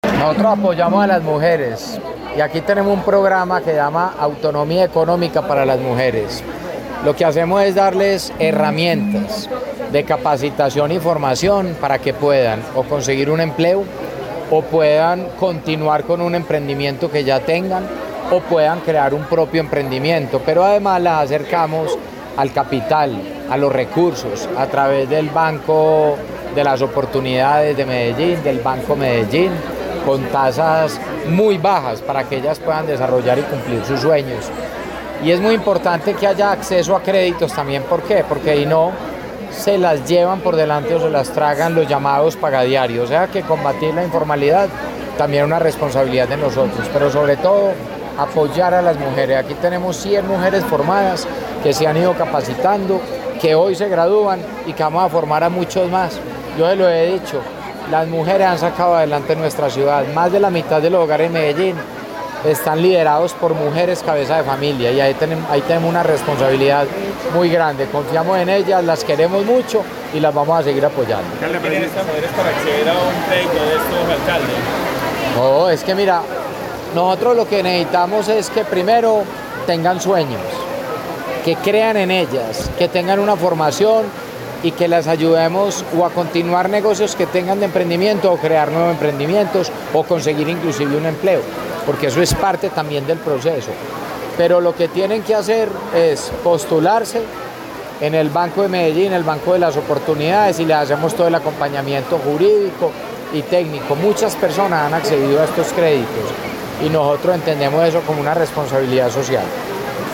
Encuentro de autonomía económica para las mujeres de la Comuna 3, Manrique.
Declaraciones-Alcalde-Federico-Gutierrez.mp3